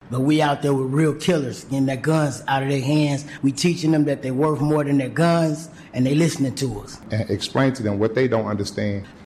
Commissioners got to hear from two of the outreach workers who use their street credibility to convince young offenders there is no future in gun violence.